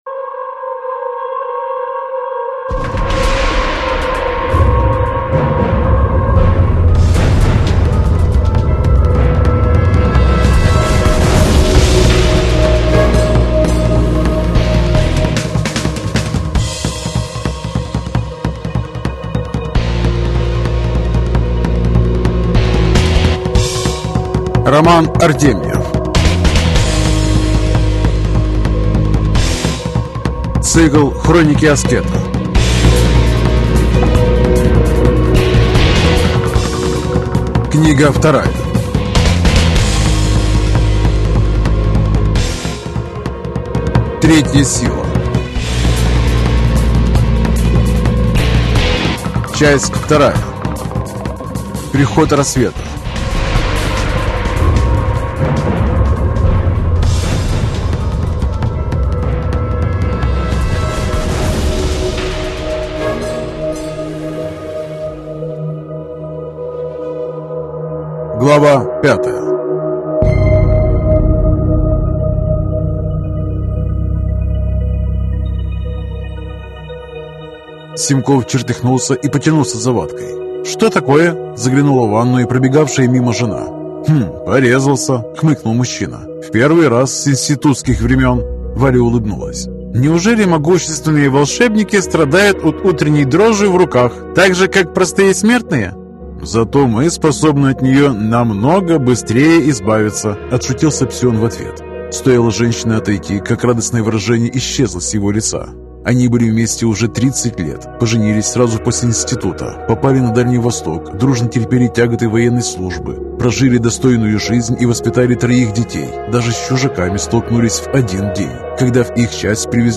Аудиокнига Третья сила. Книга 2 (том 2) | Библиотека аудиокниг